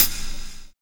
Wu-RZA-Hat 46.wav